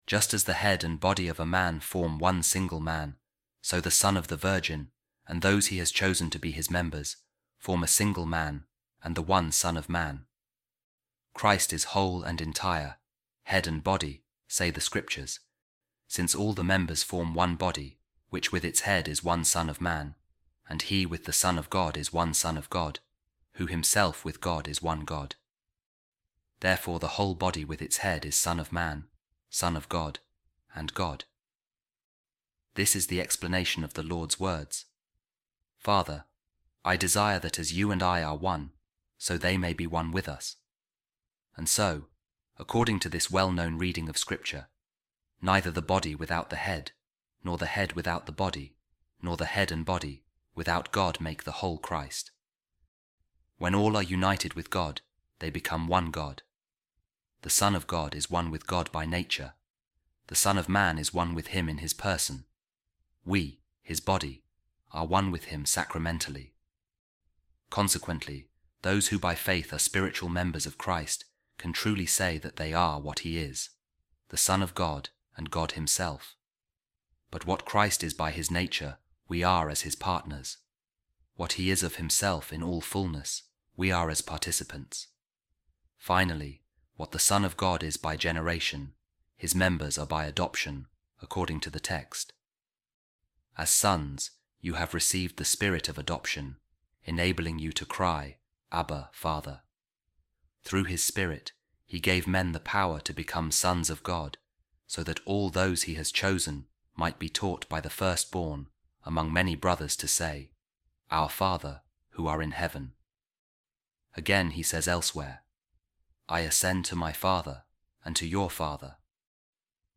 Divine Office | Office Of Readings
A Reading From The Sermons Of Blessed Isaac Of Stella | Jesus Is The First Born Of Many Brethren